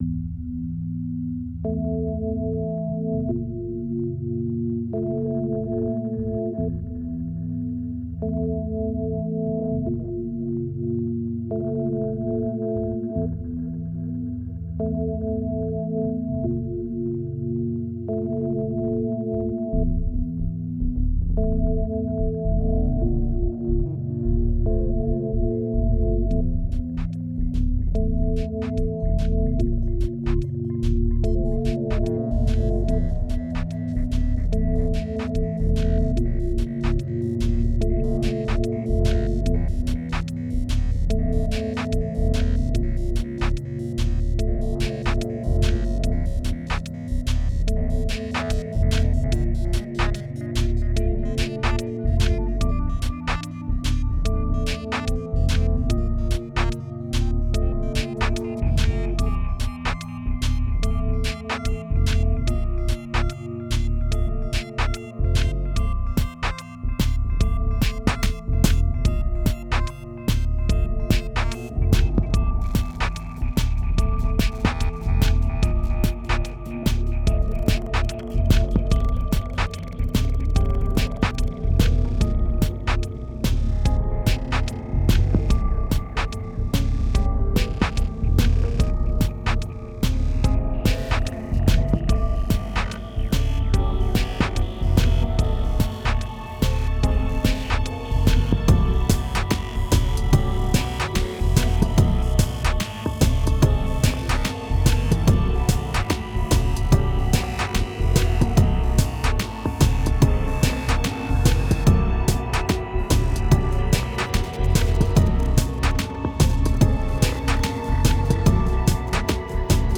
2526📈 - -13%🤔 - 73BPM🔊 - 2011-01-24📅 - -192🌟